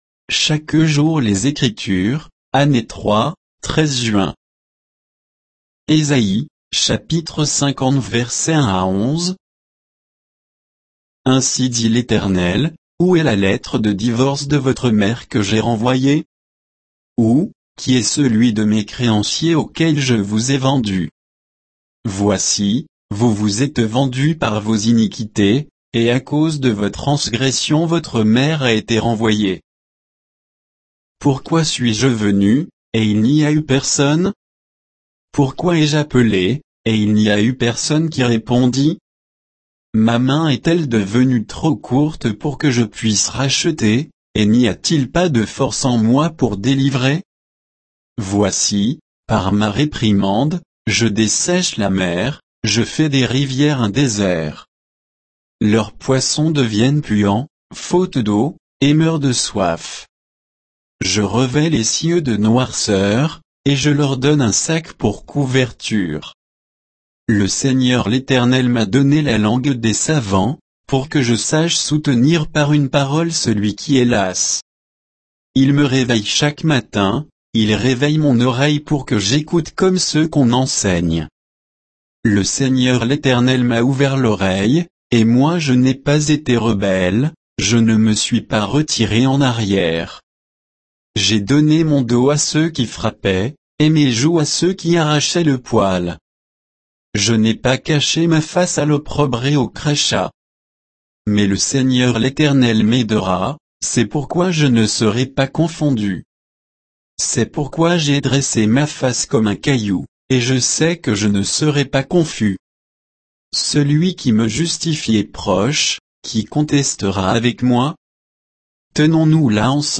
Méditation quoditienne de Chaque jour les Écritures sur Ésaïe 50